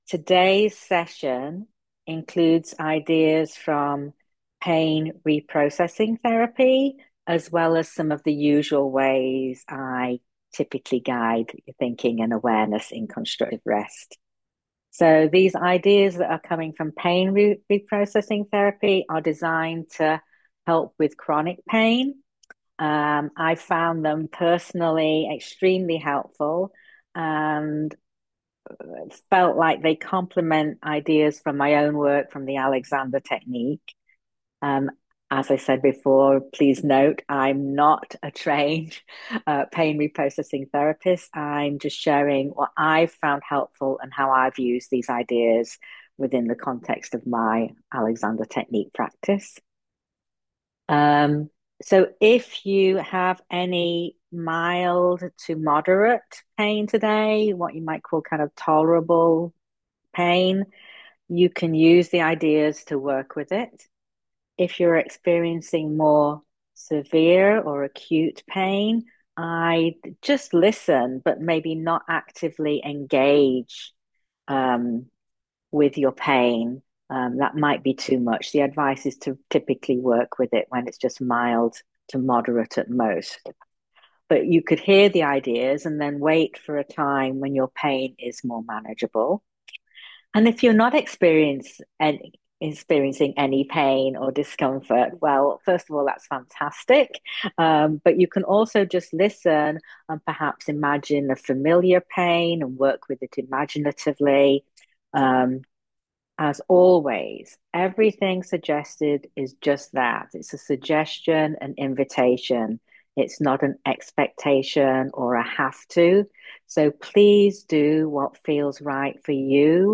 talk given during a live session on February 27, 2026. This talk includes ideas from Pain Reprocessing Therapy to help with chronic pain. Listen to it during Constructive Rest to guide your thinking and awareness.